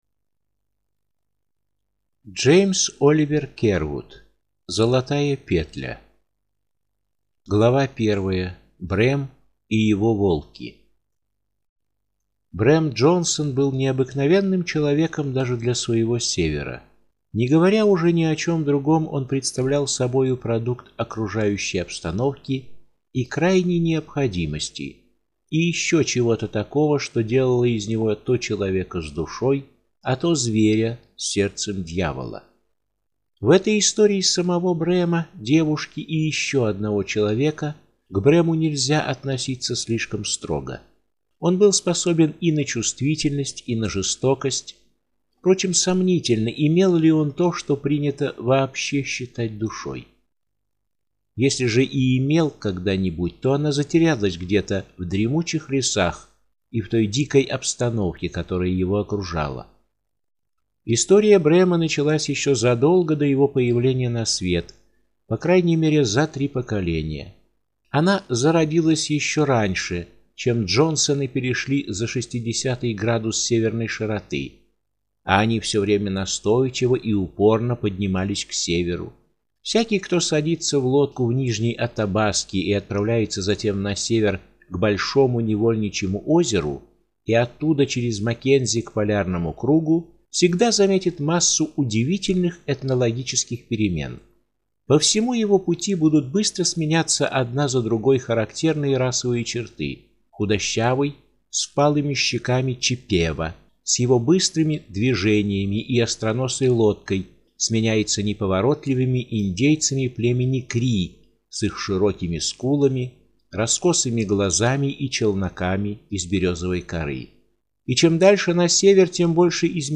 Аудиокнига Золотая петля | Библиотека аудиокниг